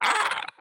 sounds / mob / strider / hurt3.ogg